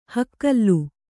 ♪ hakkallu